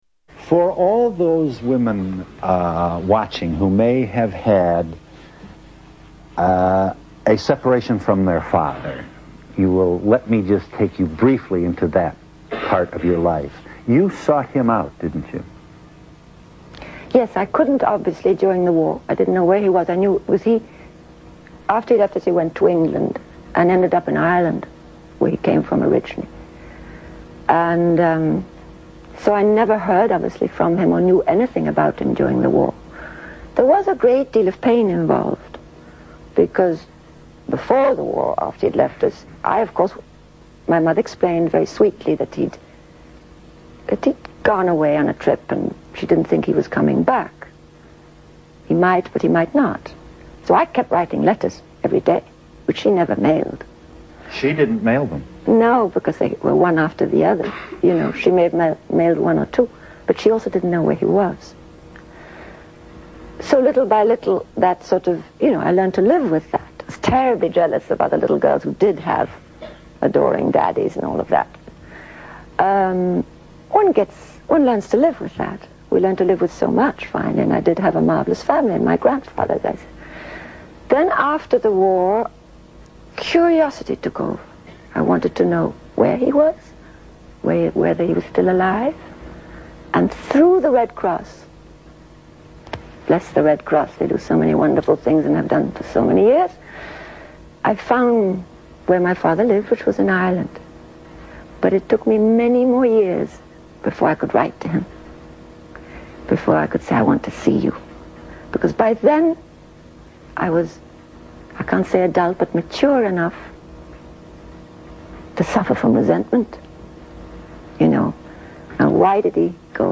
Tags: Audrey Hepburn clips Audrey Hepburn interview Audrey Hepburn audio Audrey Hepburn Actress